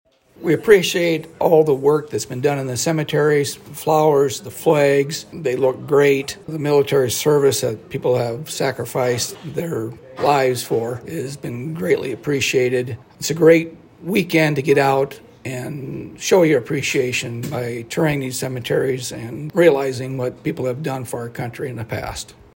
Chair of the Humboldt County Board of Supervisor, Rick Petersen, says he appreciates all the military has done and hopes others will take time to reflect: